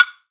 1ST-WBLOCK-R.wav